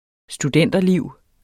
Udtale [ sduˈdεnˀdʌ- ]